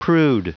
Prononciation du mot prude en anglais (fichier audio)
Prononciation du mot : prude